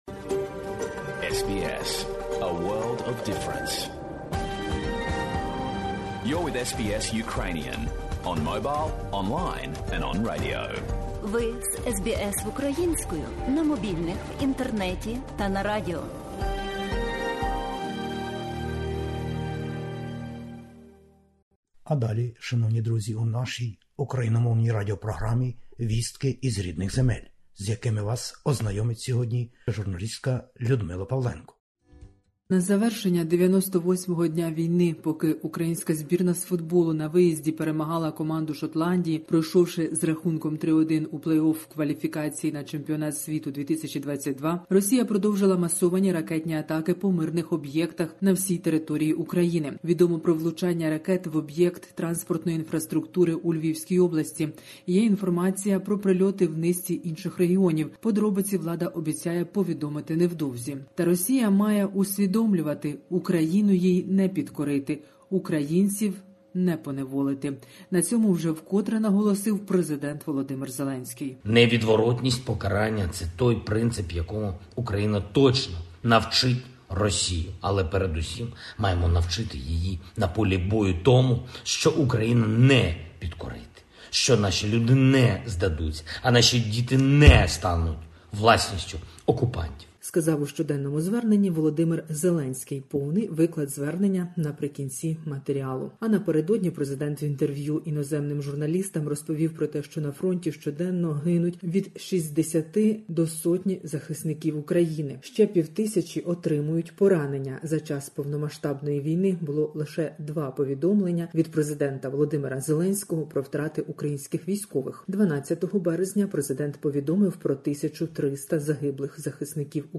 Добірка новин із героїчної України.